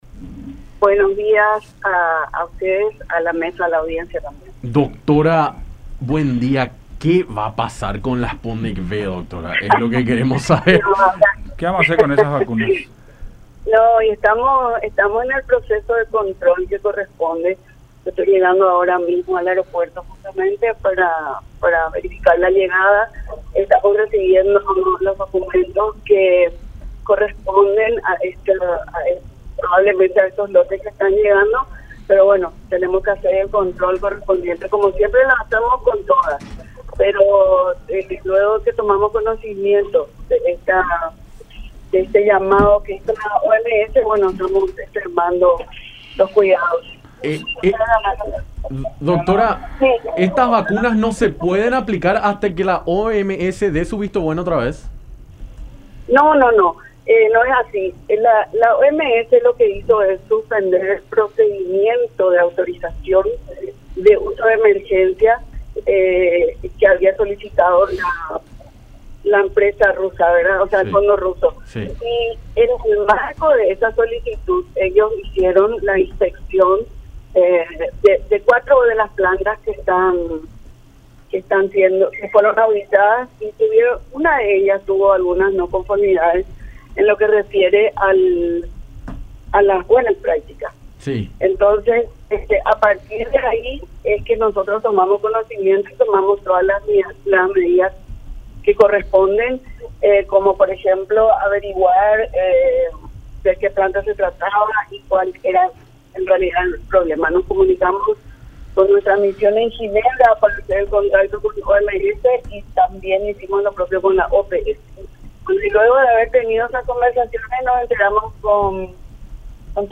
“La OMS hizo la inspección de cuatro de las plantas que fabrican la vacuna Sputnik V. En una de ellas, situada en la ciudad de Ufa, tuvo algunas disconformidades a las buenas prácticas. A partir de ahí tomamos conocimiento de lo sucedido y tomamos las precauciones necesarias que corresponden”, detalló María Antonieta Gamarra, titular de DINAVISA, en diálogo con Enfoque 800 por La Unión, en referencia al cargamento de 107.000 dosis de Sputnik V (105.000 de componente 1 y 2.000 del componente 2), llegado en la mañana de este viernes a Paraguay.